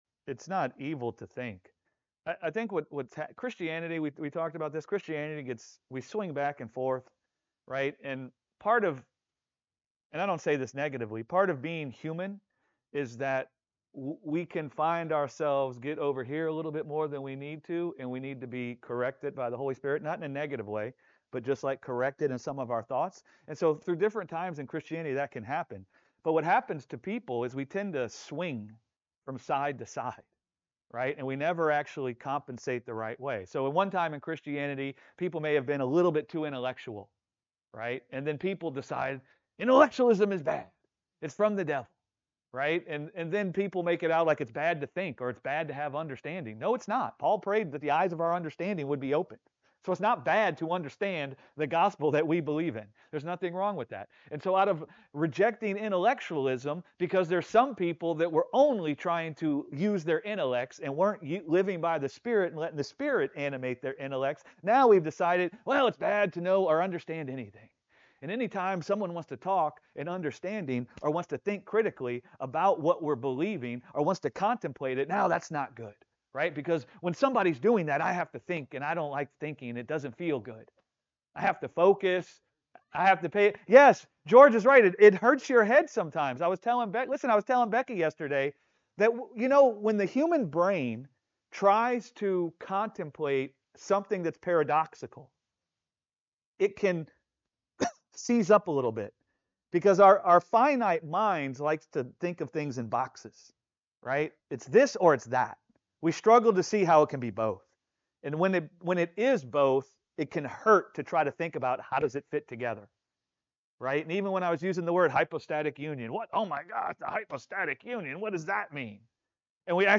ministering at Gospel Revolution Church on the revelation of Christ Jesus as the Creator of all things, allowing himself to be crucified for the purpose of making creation anew